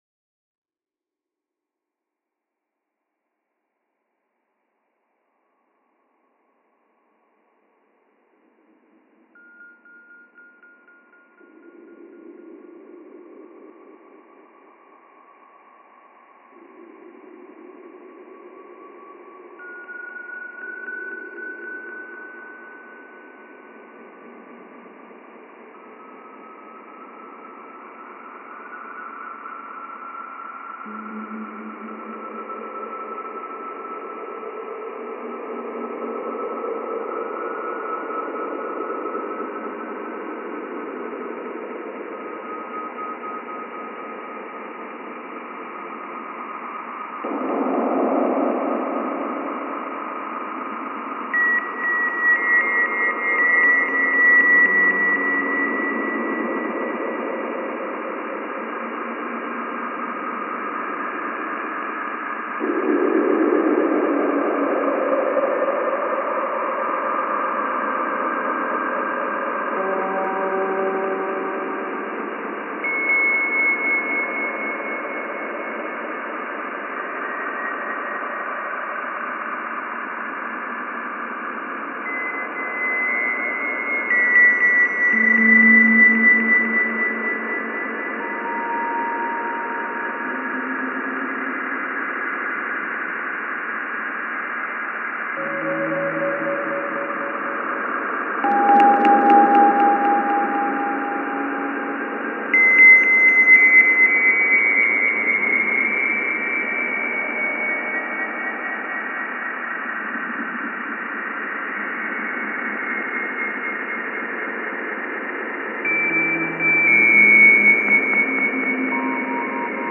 Juno is the NASA space probe studying the planet Jupiter. Juno’s instruments recorded some odd noises; I played them under a few modifications.